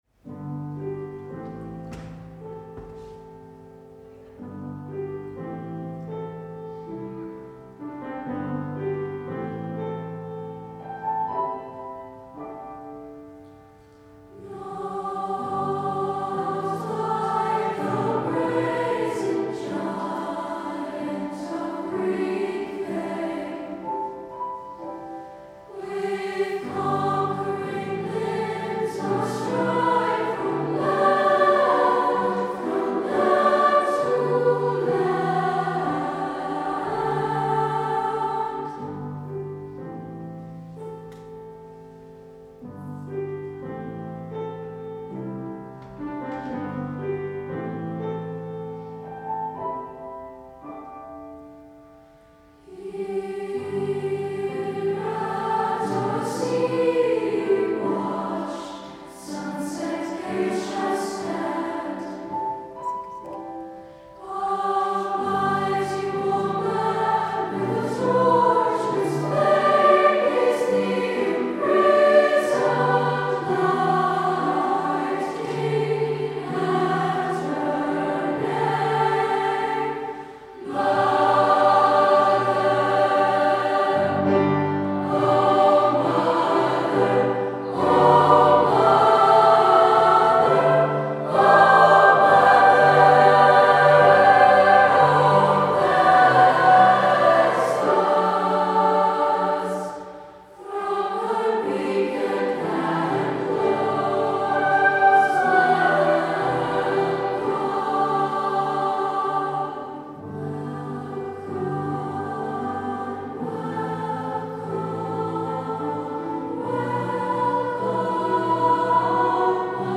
SATB Chorus, Treble Soli, Soprano Solo & Piano